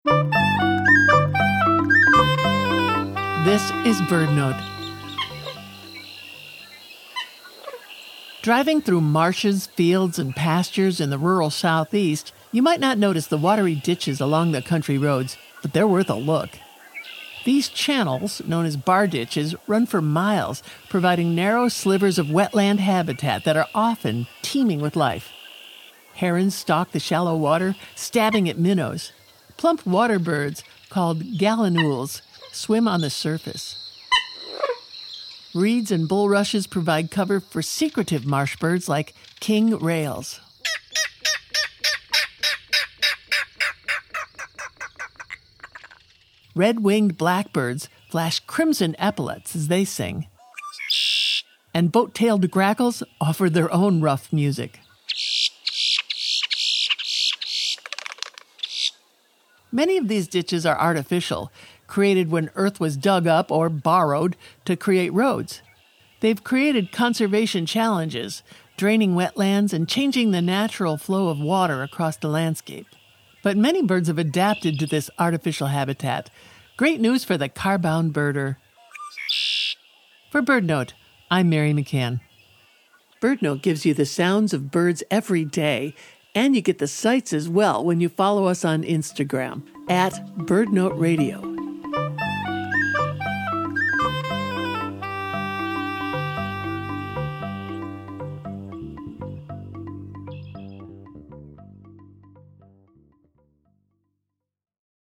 Common Gallinules, like this one, swim on the surface.
Red-winged Blackbirds flash crimson epaulets as they sing, and Boat-tailed Grackles offer their own rough music.